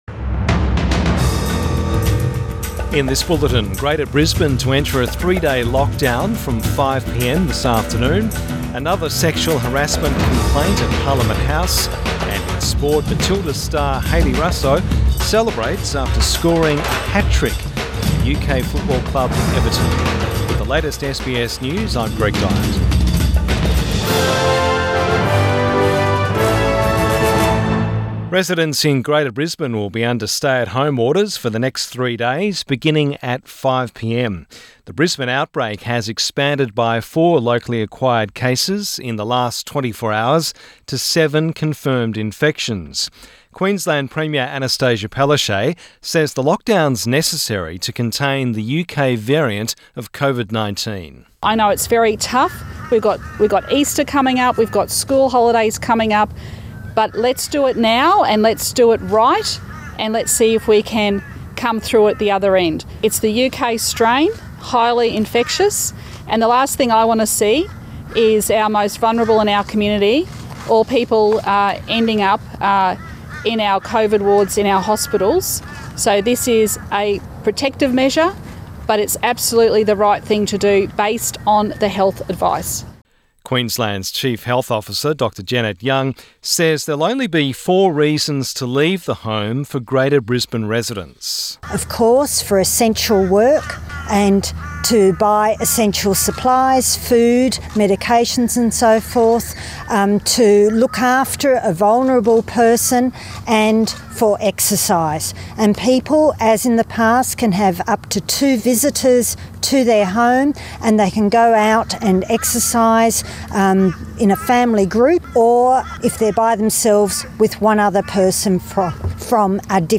Midday bulletin 29 March 2021